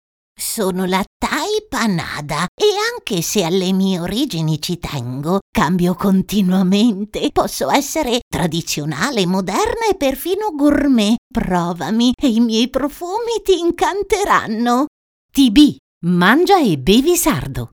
Natural, Versátil, Seguro, Maduro, Suave